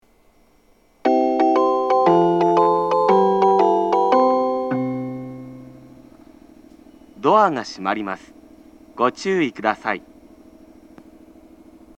発車メロディー
一度扱えばフルコーラス鳴ります。
正月は2回扱うこともあるそうです。